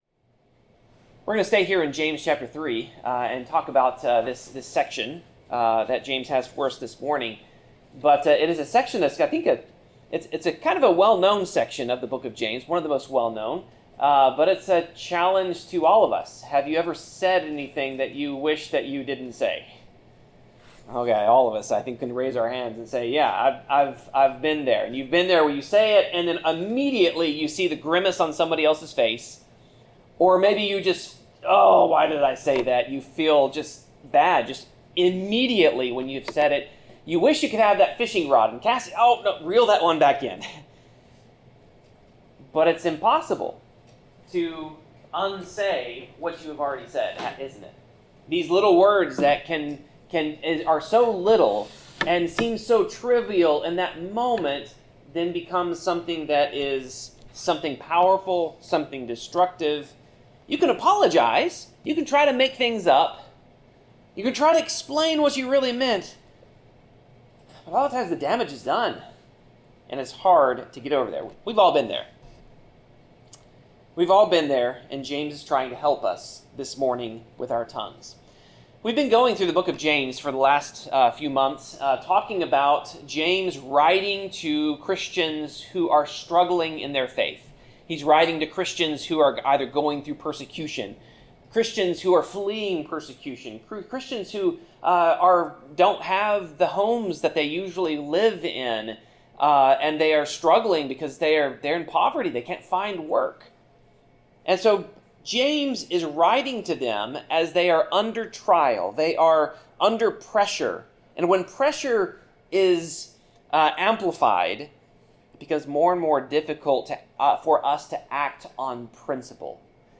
Passage: James 3:1-12 Service Type: Sermon